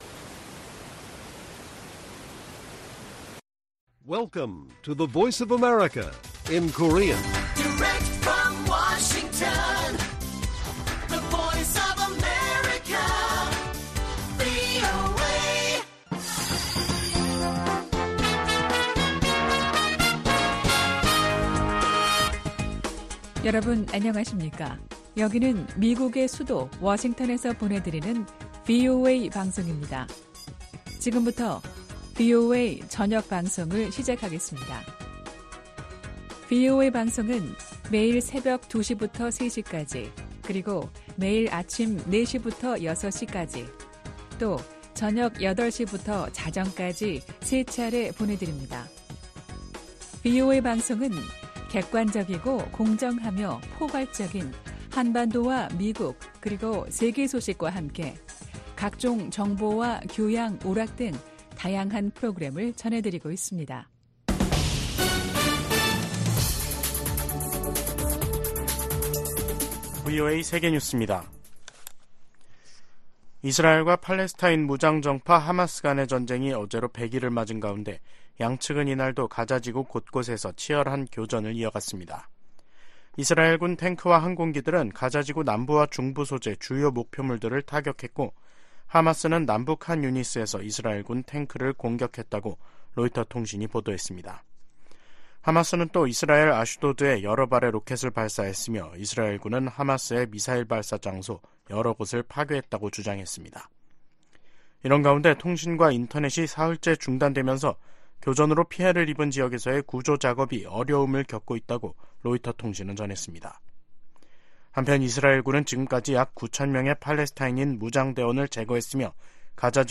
VOA 한국어 간판 뉴스 프로그램 '뉴스 투데이', 2023년 1월 15일 1부 방송입니다. 북한은 신형 고체연료 추진체를 사용한 극초음속 중장거리 탄도미사일(IRBM) 시험 발사에 성공했다고 발표했습니다. 미국은 북한의 새해 첫 탄도미사일 발사를 유엔 안보리 결의 위반이라며 대화에 나설 것을 거듭 촉구했습니다. 중국 선박이 또다시 북한 선박으로 국제기구에 등록됐습니다.